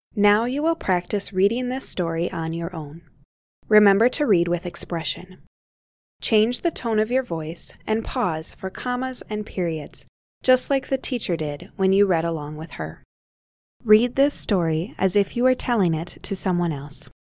audio instruction reminding them to practice reading with expression.